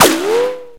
ricochet sounds